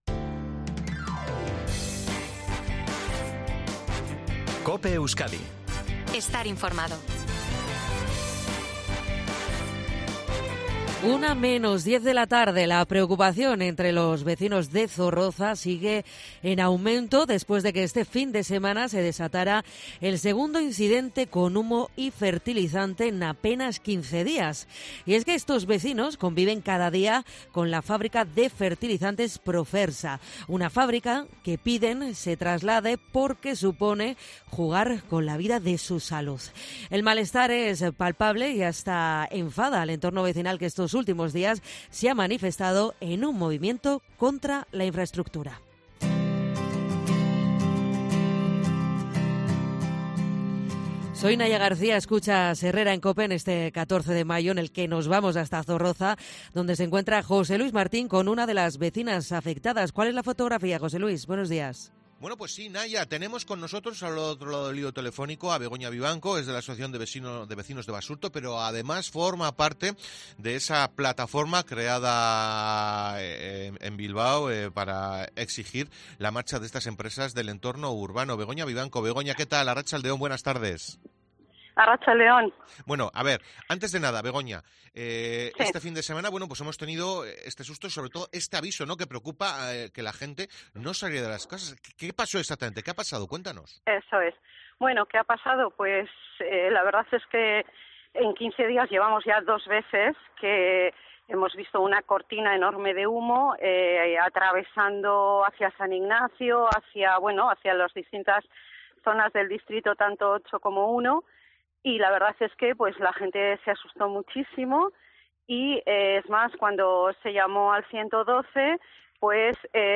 Asociaciones de vecinos denuncian, en COPE Euskadi, el malestar que han generado los dos incidentes de humo provocados por la fábrica Profersa, ubicada en el barrio